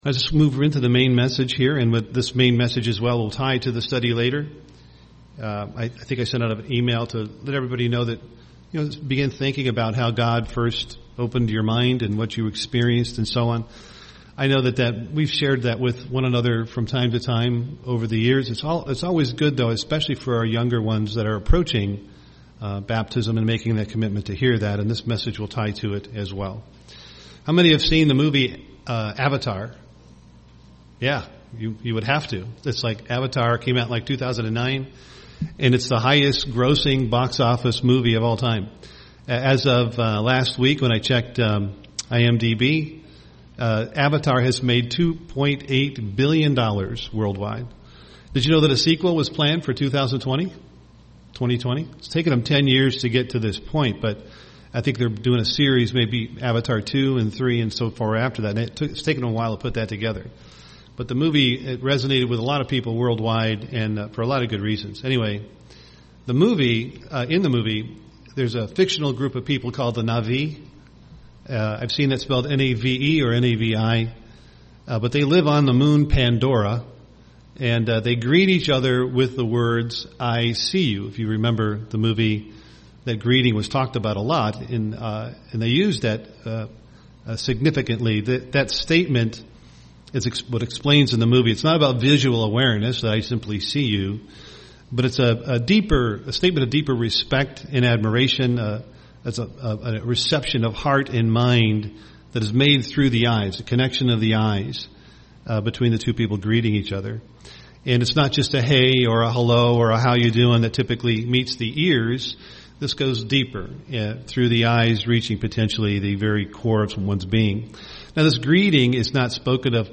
UCG Sermon hearing God Job Studying the bible?